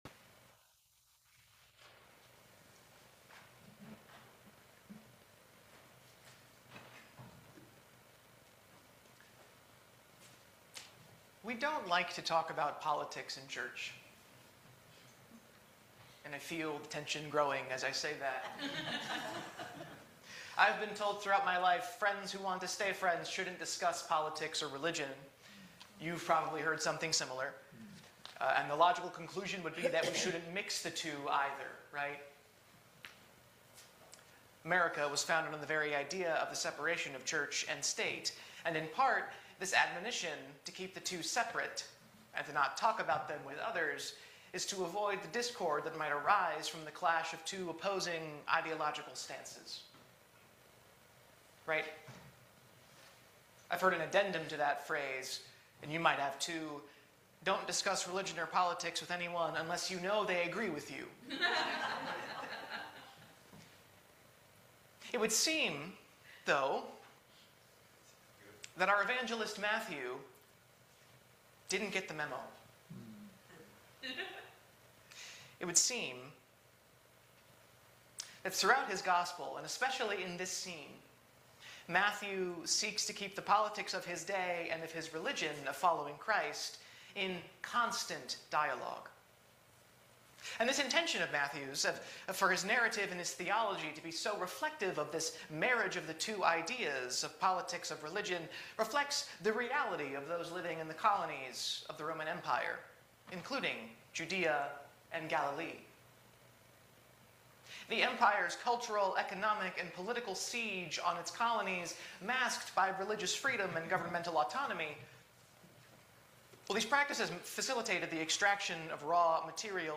Matthew 21:1-11 Service Type: Sunday Service Our hosannas must mean “Hosanna